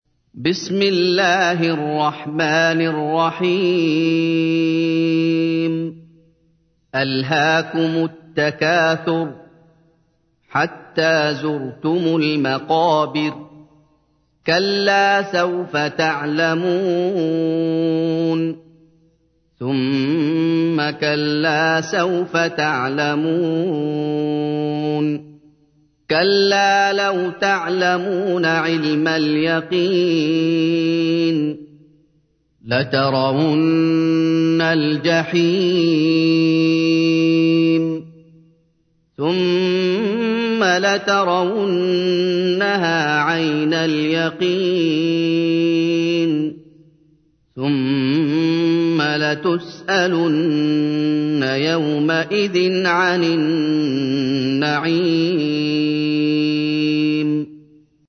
تحميل : 102. سورة التكاثر / القارئ محمد أيوب / القرآن الكريم / موقع يا حسين